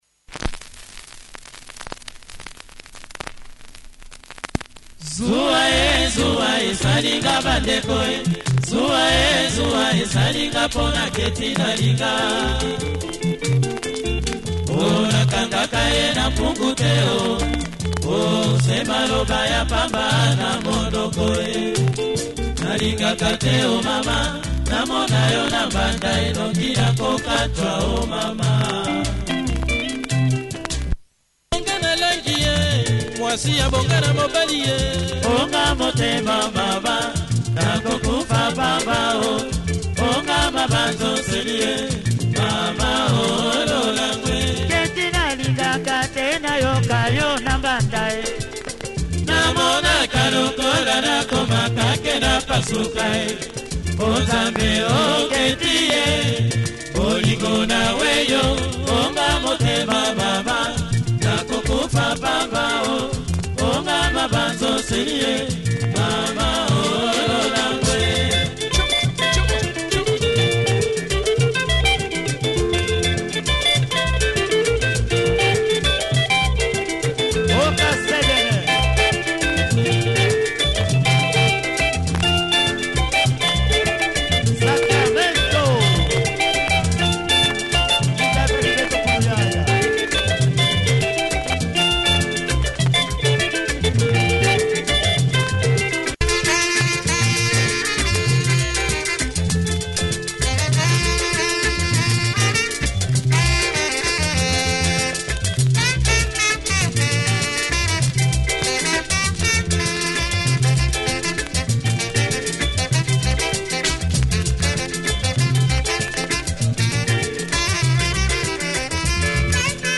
Classic Lingala
best horn section around!